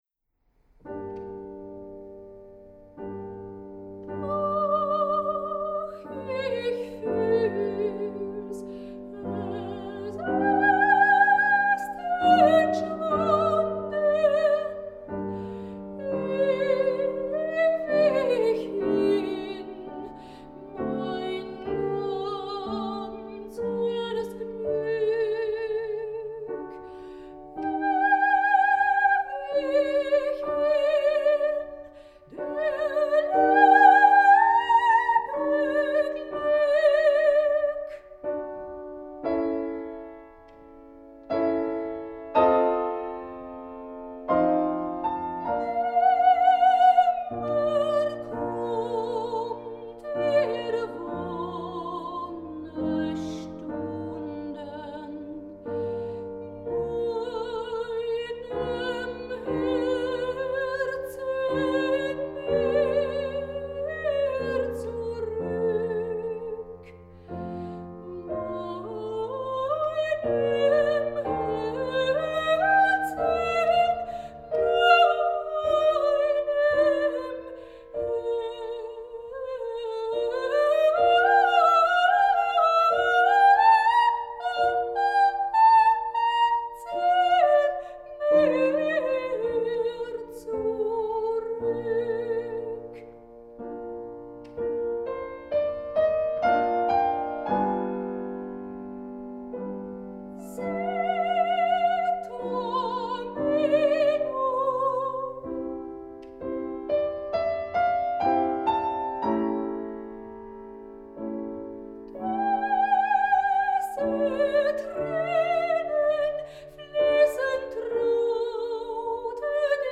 Sopran
2007: Demo-CD: Yamaha-Hall, Wien: Zauberflöte-Pamina: